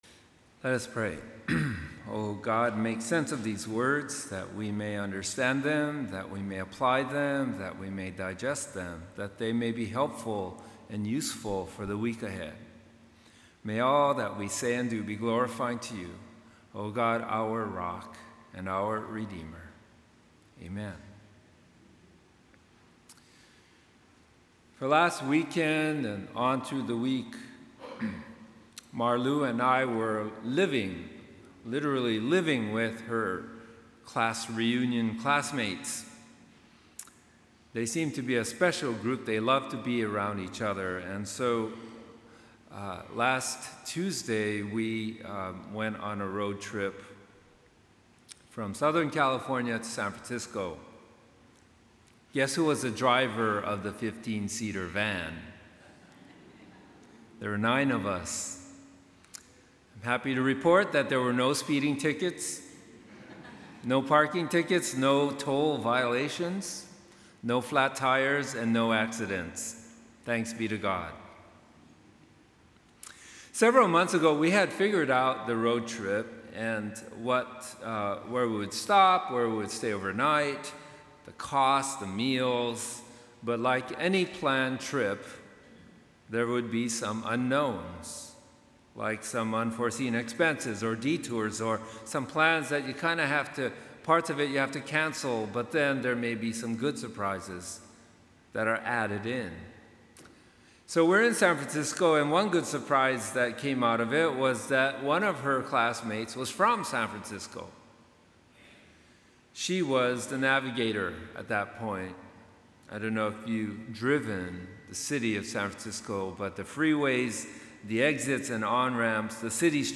9:30 AM Worship